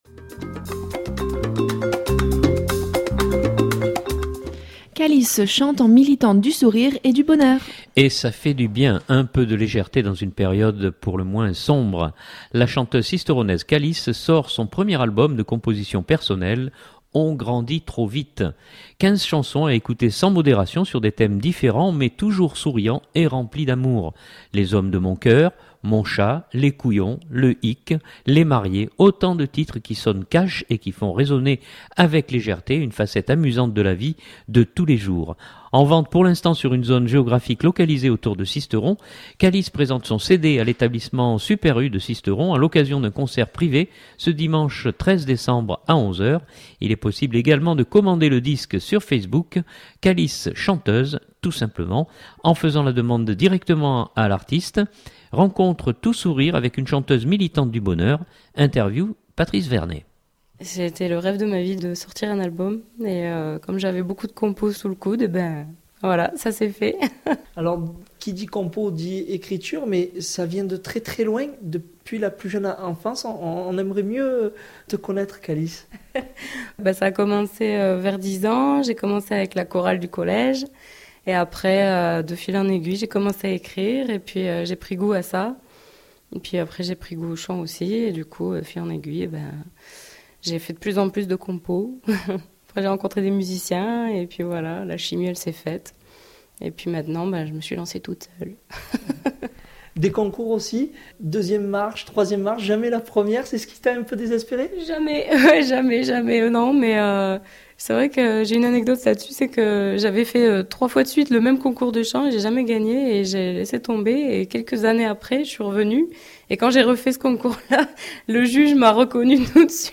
Rencontre tout sourire avec cette chanteuse militante du bonheur.